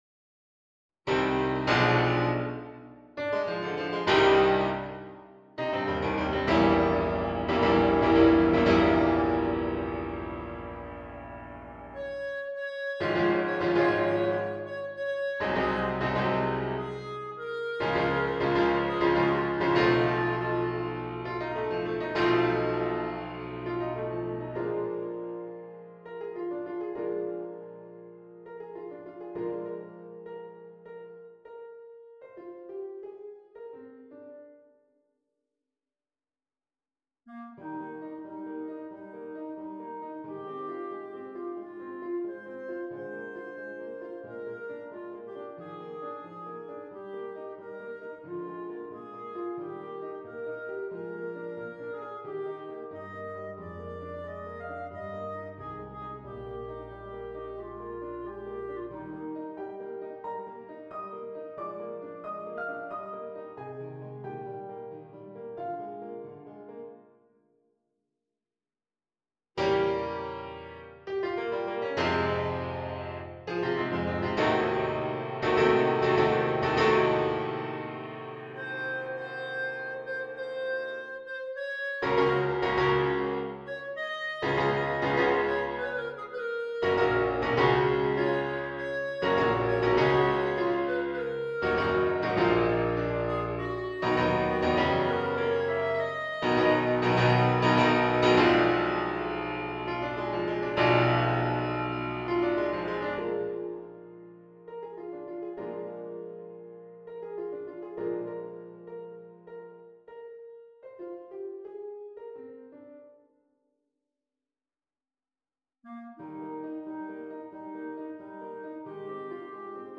Bb clarinet